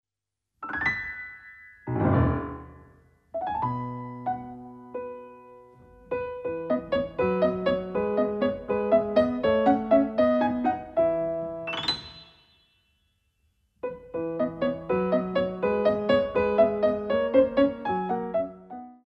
Ballet Class Music For Children aged 5+